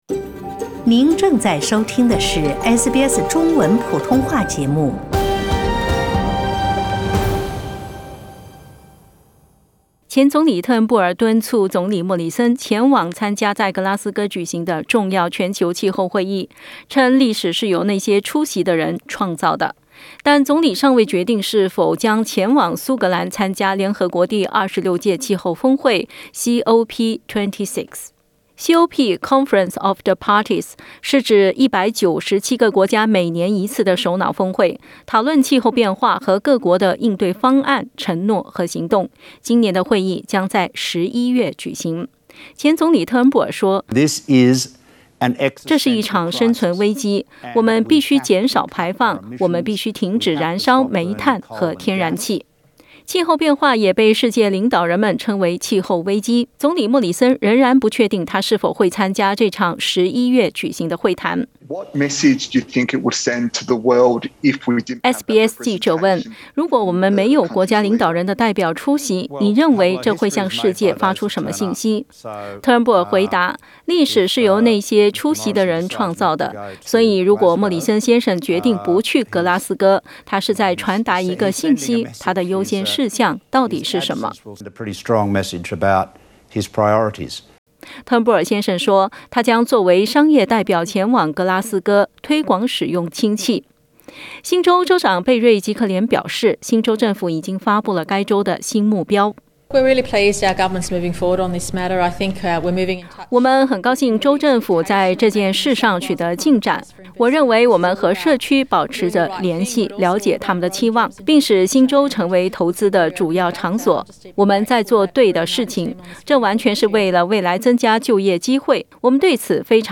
前总理特恩布尔在接受SBS采访时表示，如果莫里森决定不去格拉斯哥，那么他传达的信息就是：气候变化不是澳大利亚优先考虑的事项。